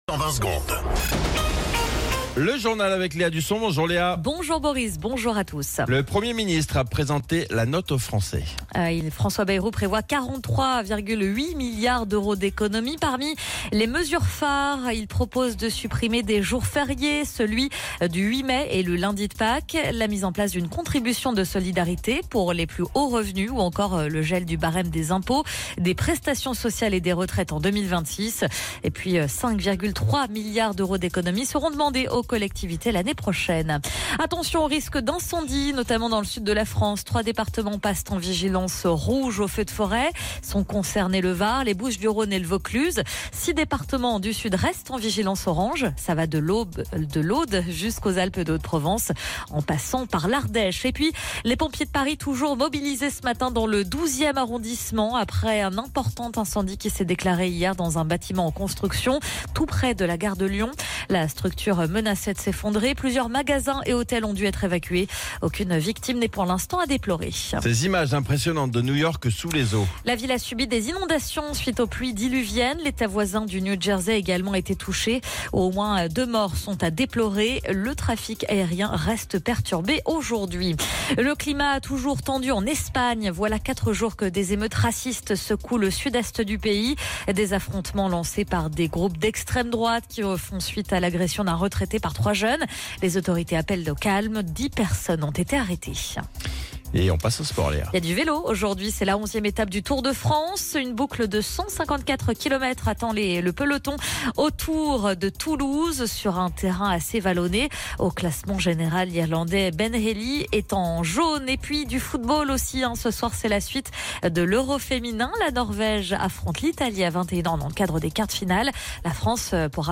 Flash Info National 16 Juillet 2025 Du 16/07/2025 à 07h10 .